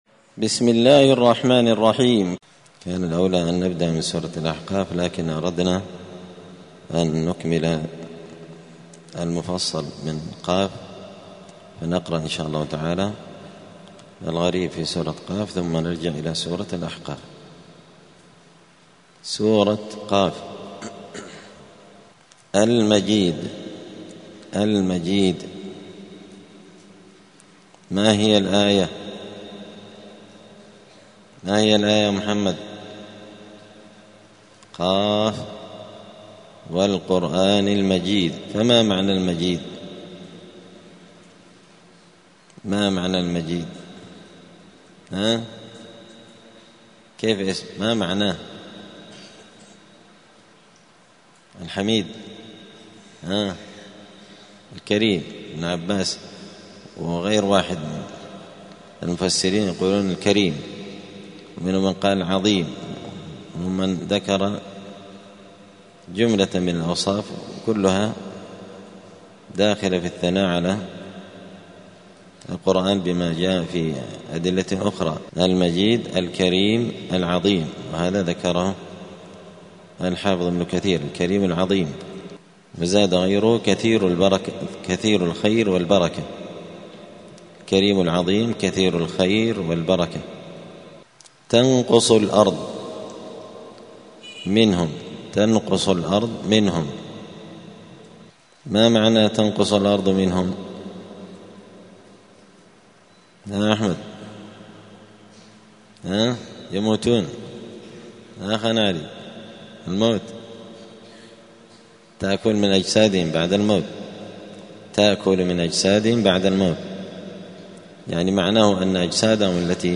الثلاثاء 21 ربيع الأول 1446 هــــ | الدروس، دروس القران وعلومة، زبدة الأقوال في غريب كلام المتعال | شارك بتعليقك | 17 المشاهدات